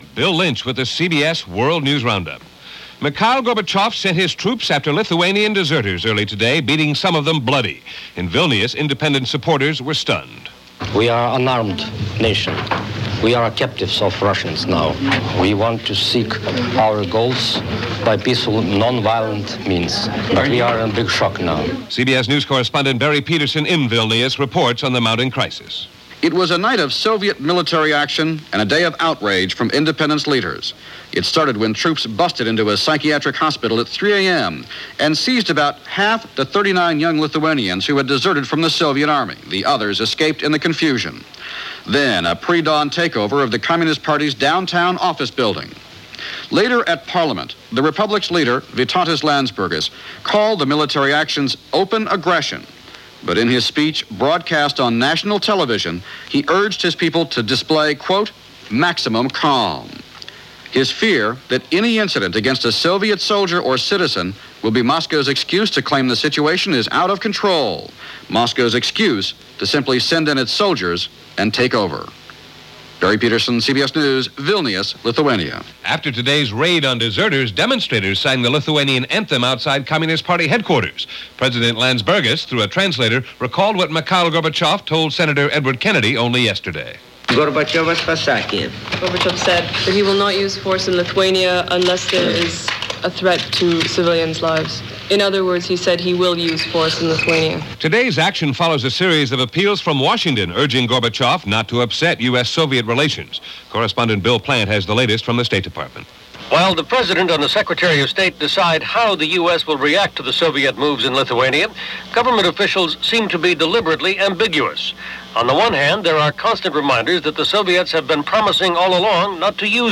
March 27, 1990 – CBS World News Roundup – Gordon Skene Sound Collection –
And so much more went on, this March 27 in 1990 – as reported by The CBS World News Roundup.